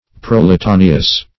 Search Result for " proletaneous" : The Collaborative International Dictionary of English v.0.48: Proletaneous \Prol`e*ta"ne*ous\, a. [L. proletaneus.] Having a numerous offspring.